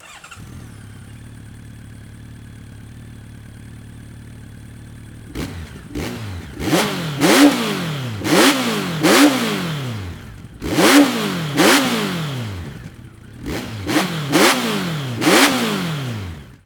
それでは排気音を収録してきたのでどうぞ・・
全く同じ距離、同じ入力レベルで収録していますが
まずアイドリングの音量が全く違っていて
エンジンを回すと純正マフラーをうるさくしている低音域が
音圧が抑え込まれています。